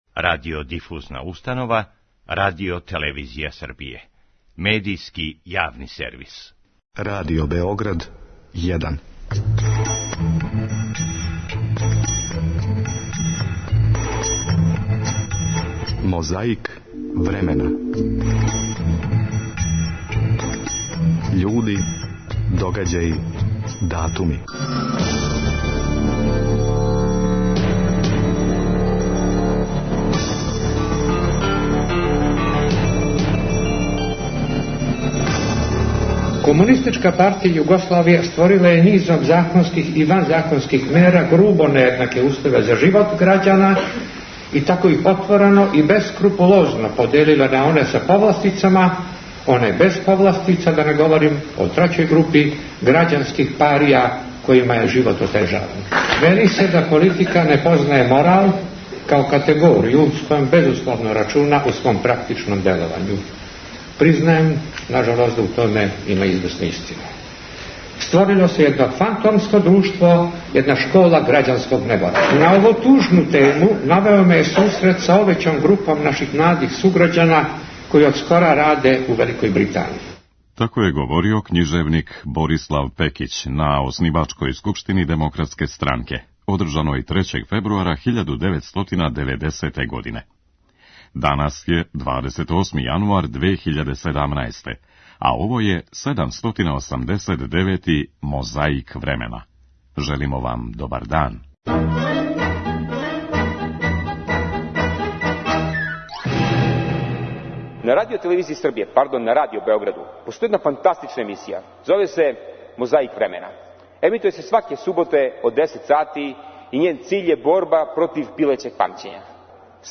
Оснивачка скупштина Демократске странке одржана је трећег фебруара 1990. године у Дому Омладине у Београду.
Радио Б92 пренео је обраћање Зорана Ђинђића окупљеним грађанима.
Подсећа на прошлост (културну, историјску, политичку, спортску и сваку другу) уз помоћ материјала из Тонског архива, Документације и библиотеке Радио Београда.